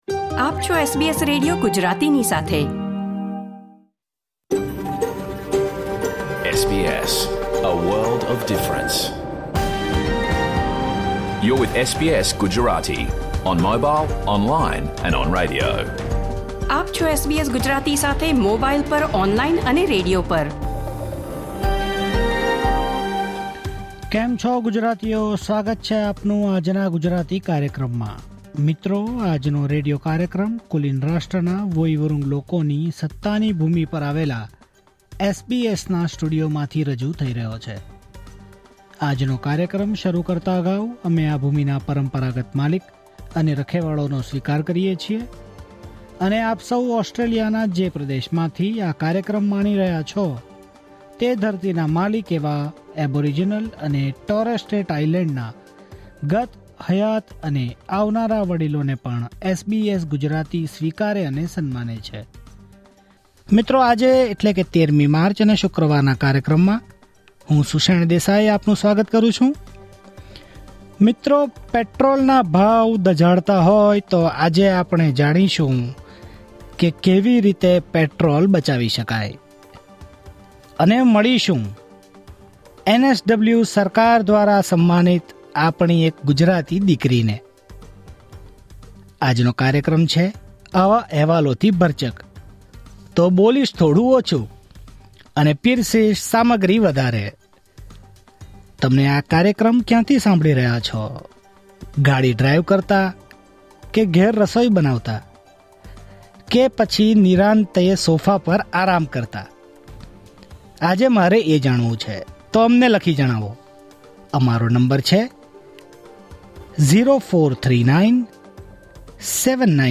Listen to the full SBS Gujarati radio program